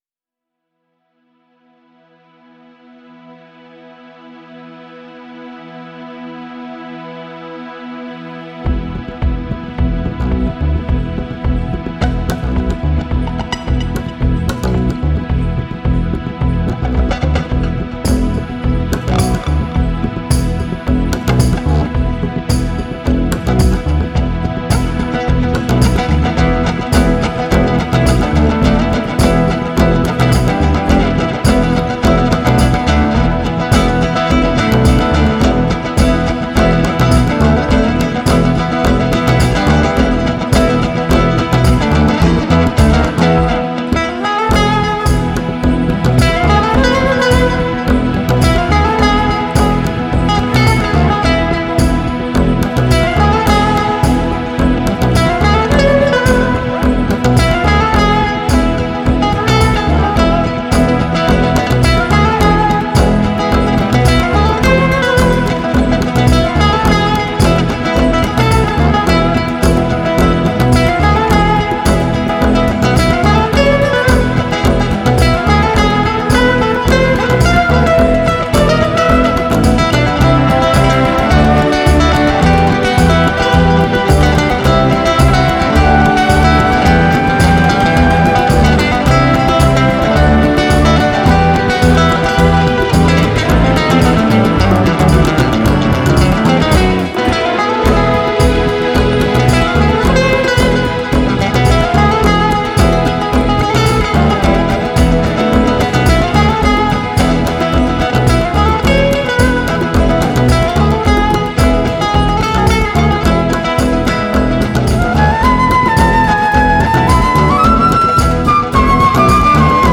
live instrumental album
GK Guitar
Harmonicas
Flute
Percussion